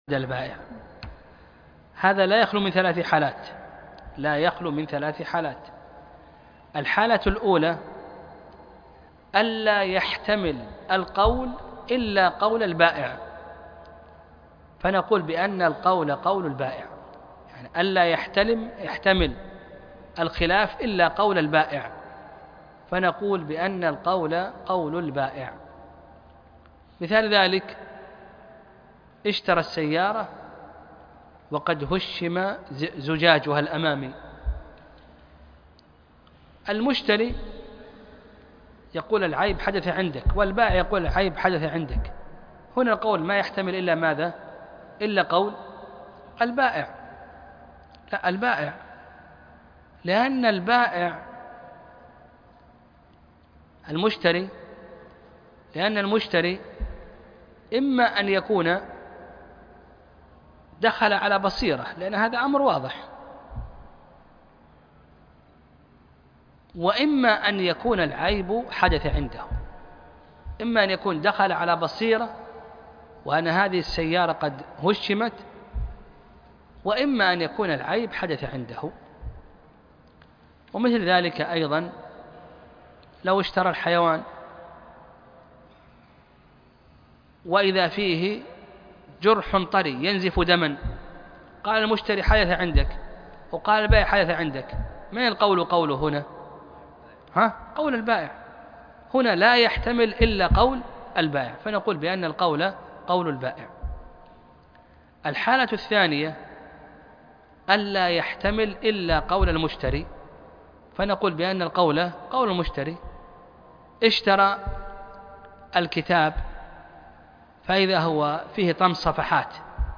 الدرس ( 3)بيع ما به عيب - شرح أخصر المختصرات